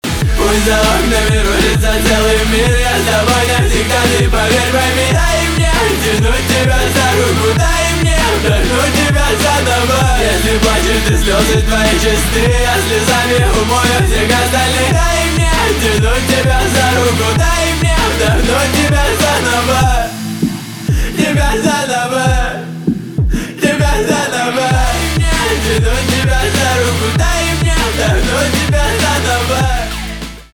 русский рок , гитара , барабаны